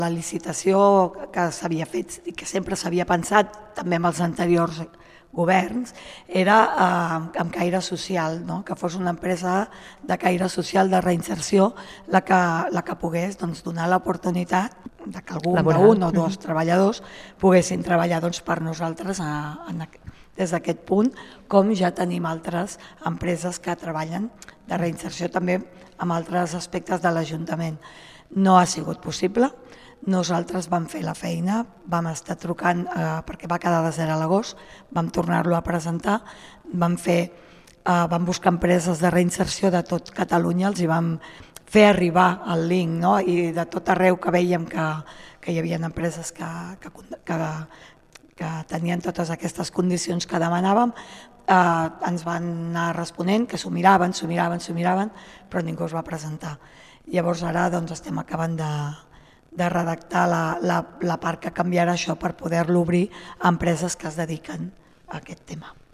Per aquest motiu, ara l'Ajuntament ha de refer les bases de la licitació per obrir aquestes tasques a tot tipus d'empreses de serveis. Així ho ha explicat la regidora de mobilitat, Montse Cervantes (Avancem Junts), a la Roda Política de Ràdio Argentona.